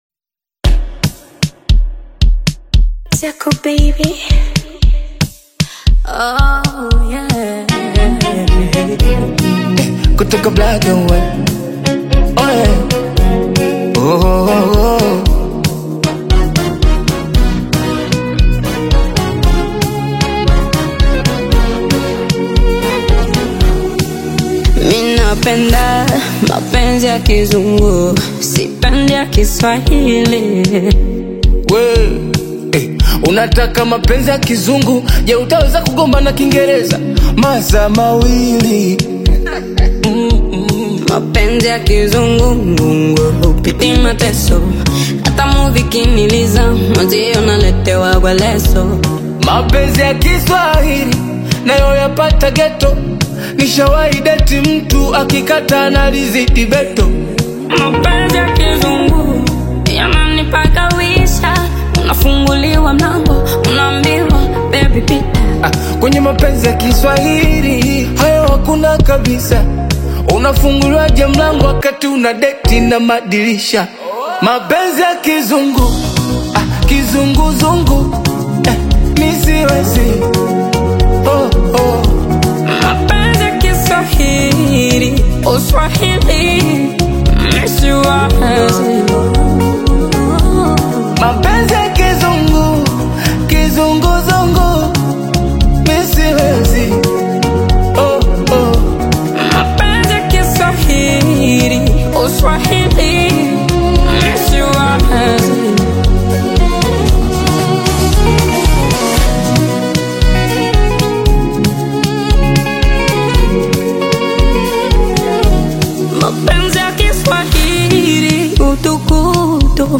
Latest Tanzania Afro-Beats Single (2026)
Genre: Afro-Beats